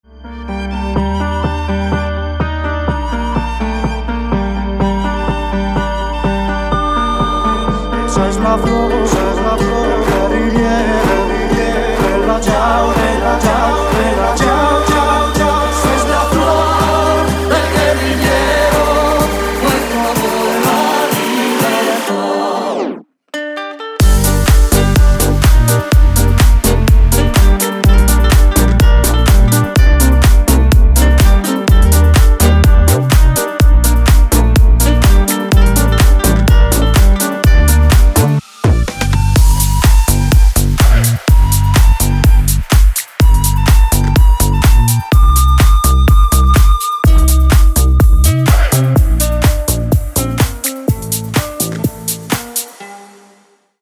• Качество: 256, Stereo
гитара
мужской вокал
remix
deep house
EDM
скрипка
Флейта